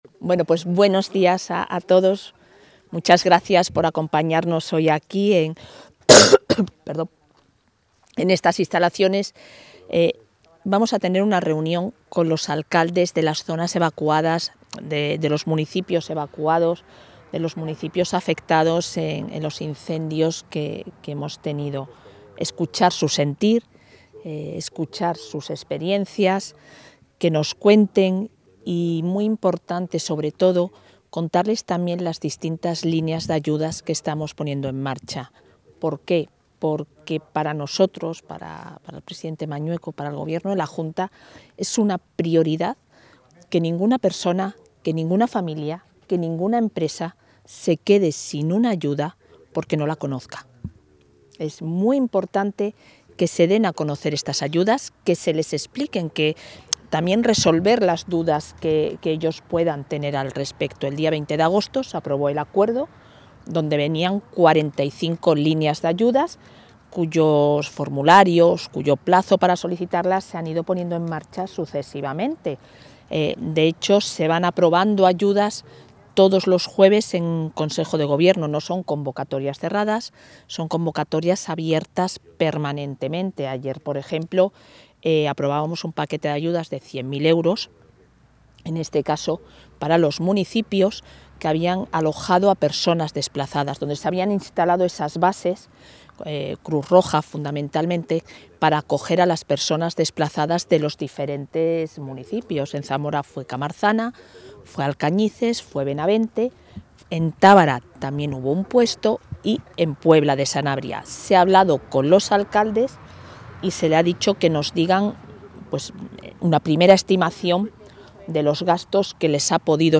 Declaraciones de la vicepresidenta.